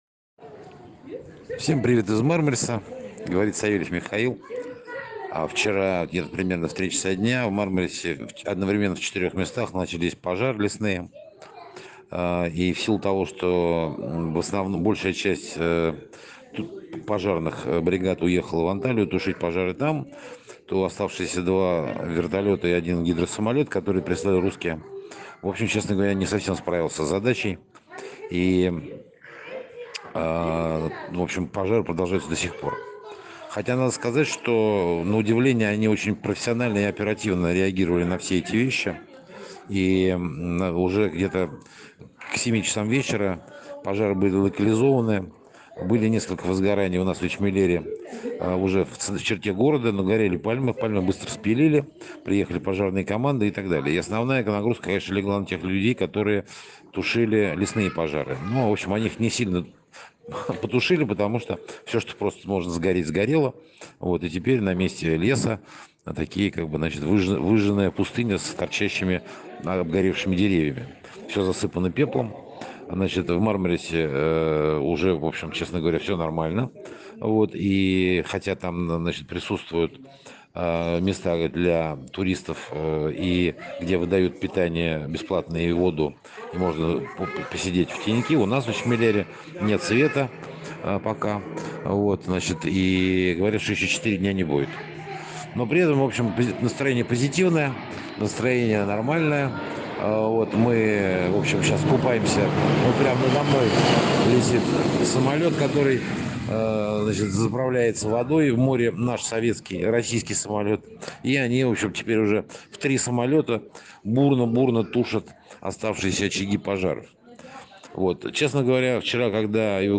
Лесные пожары на турецких курортах. Рассказ очевидца из Мармариса